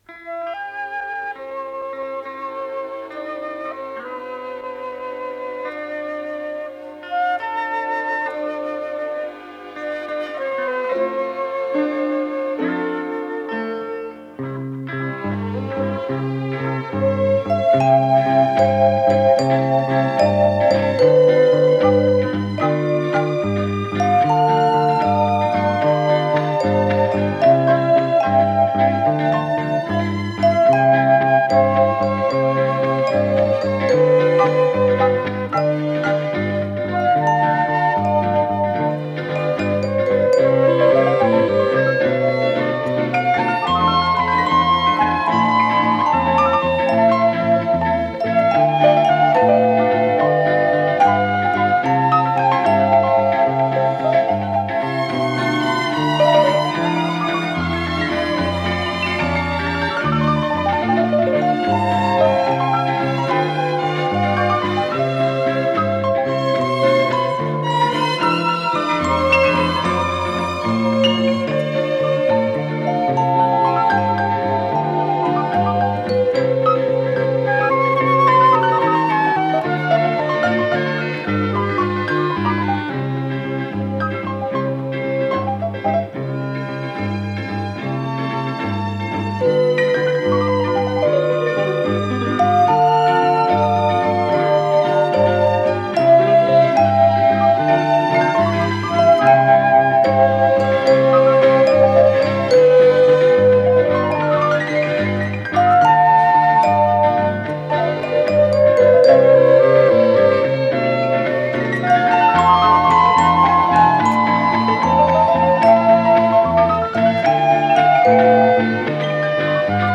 ПодзаголовокИнструментальная заставка
ВариантДубль моно